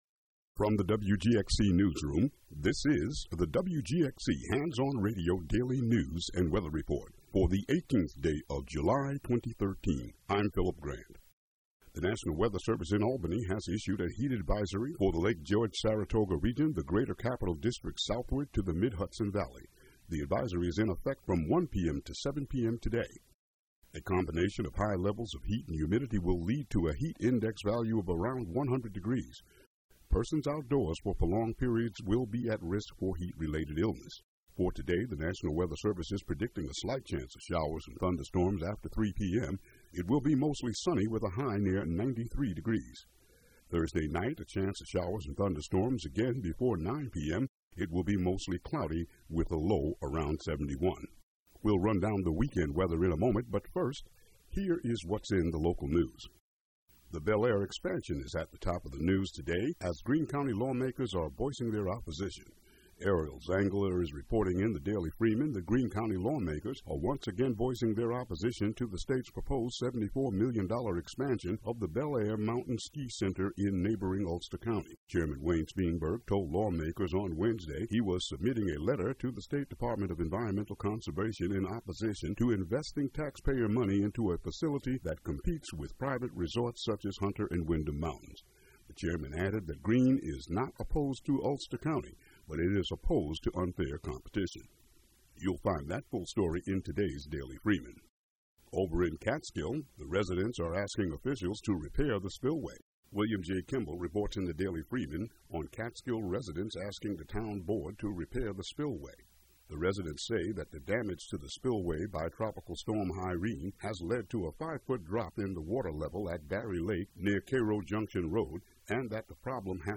Local news and weather for Wednesday, July 18, 2013.